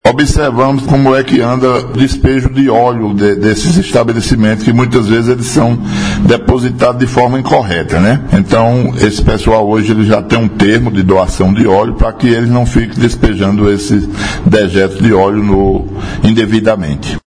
Fala do secretário municipal de Meio Ambiente, Natércio Alves –